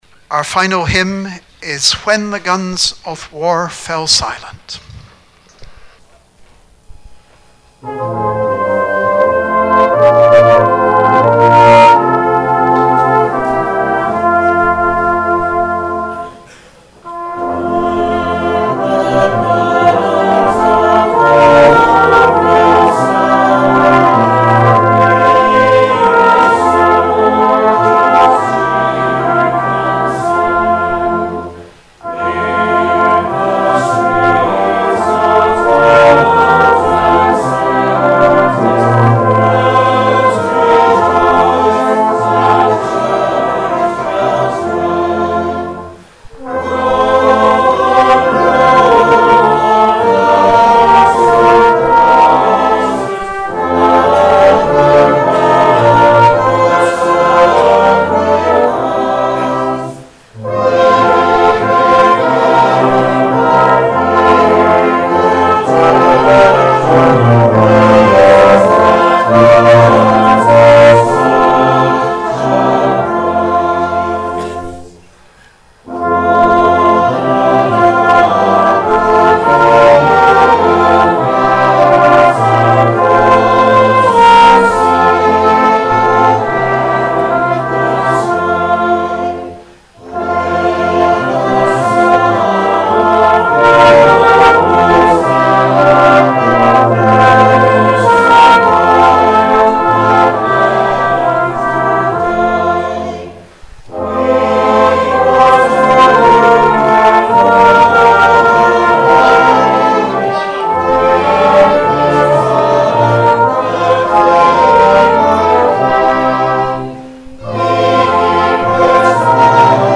Remembrance Sunday - 10 November 2019
Our final hymn, during which the colours were returned was '